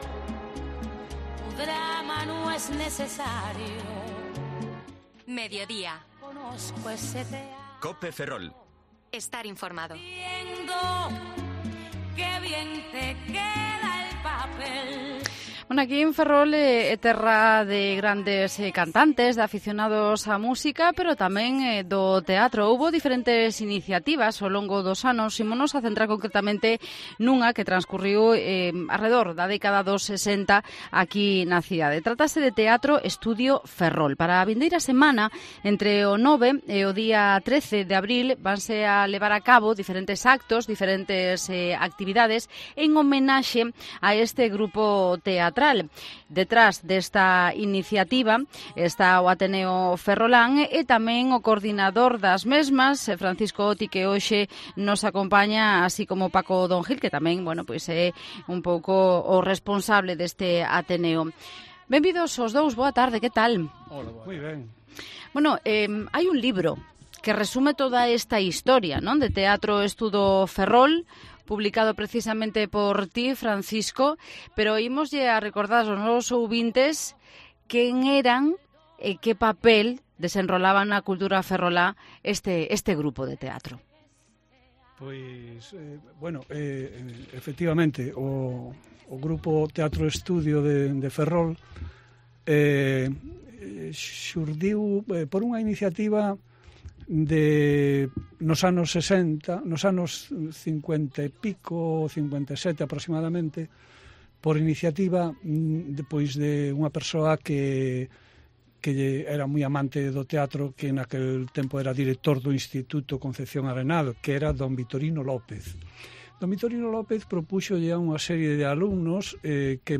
en los estudios de COPE Ferrol